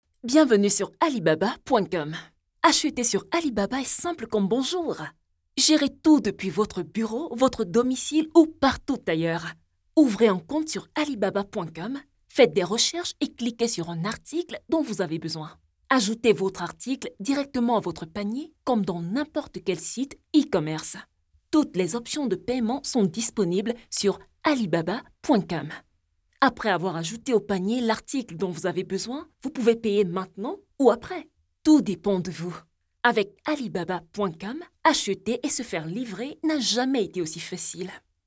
Voix off
Bande Son demo
Ayant la capacité d'effectuer une pluralité de timbres Vocaux en fonction du projet.